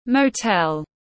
Motel /məʊˈtel/